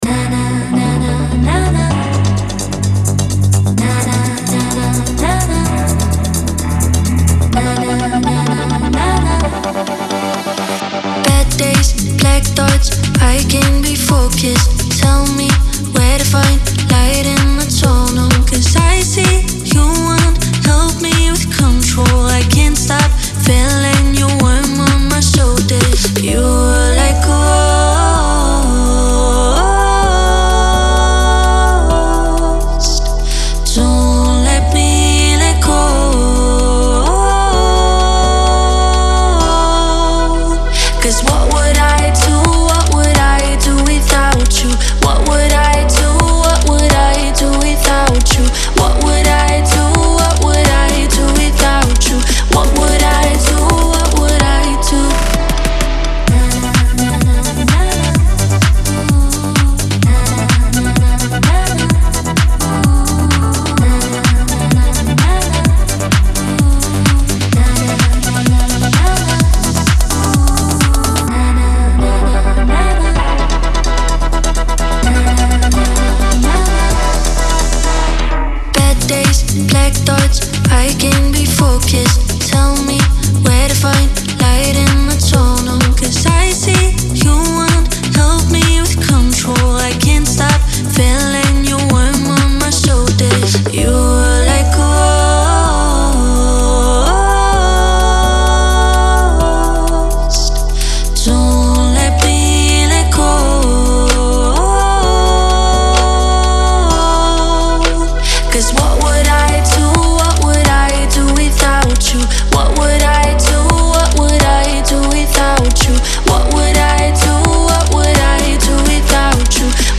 танцевальная музыка